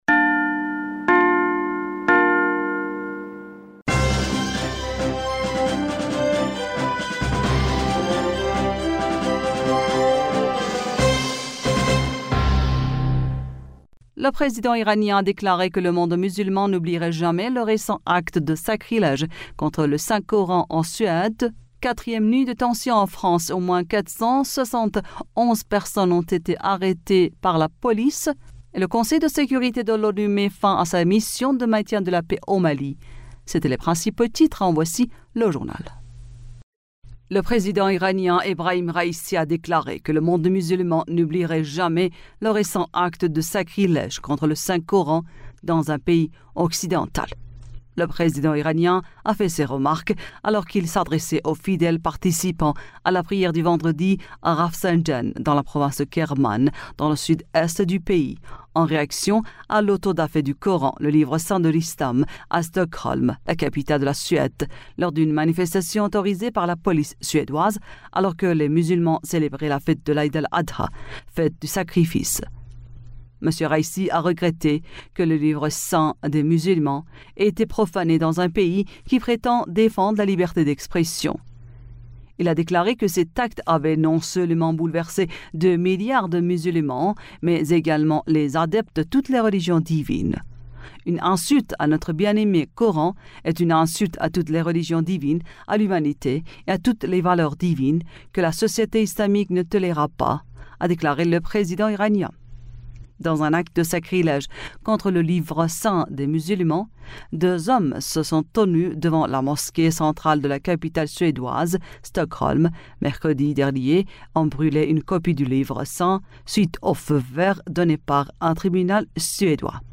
Bulletin d'information du 01 Juillet 2023